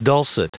Prononciation du mot dulcet en anglais (fichier audio)
Prononciation du mot : dulcet